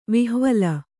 ♪ vihvala